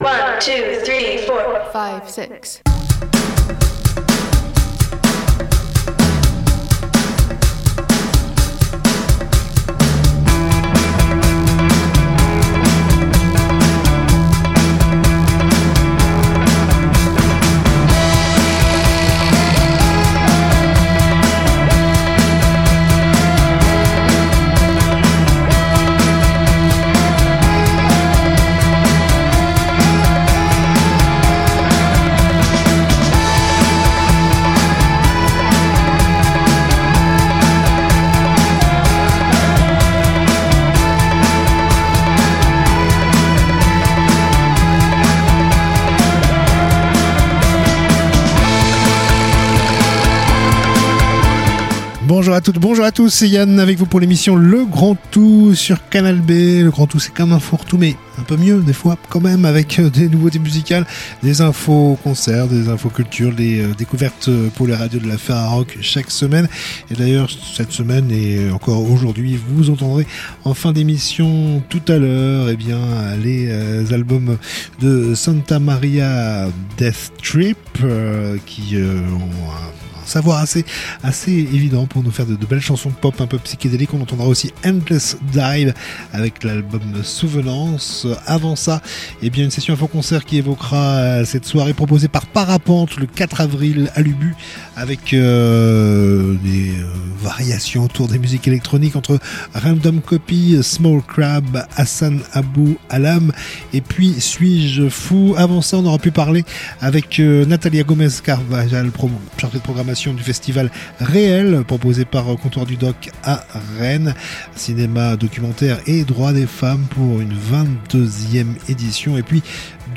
Funk kraut